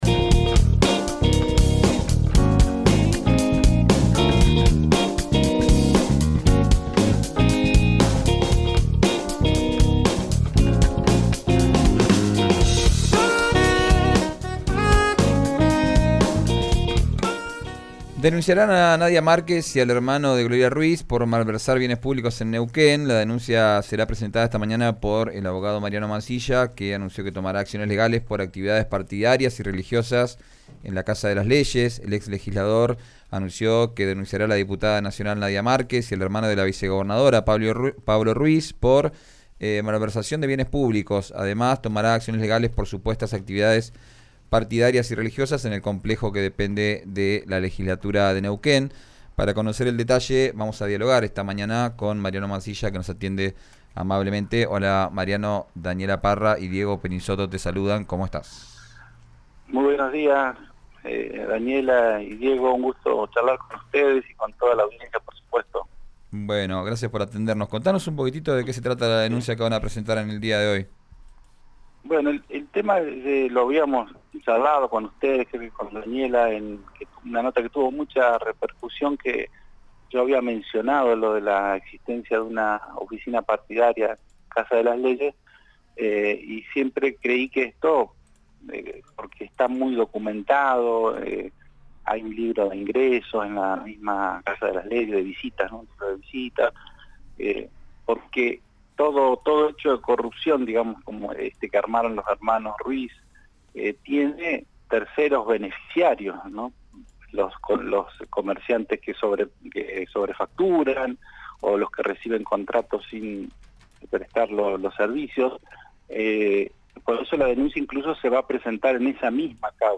Escuchá a Mariano Mansilla en RÍO NEGRO RADIO: